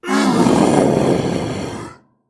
hell_dog4_die.ogg